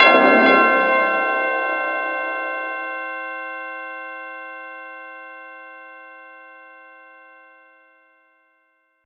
FX [ Brass Tho ].wav